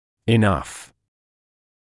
[ɪ’nʌf][и’наф]достаточно; достаточный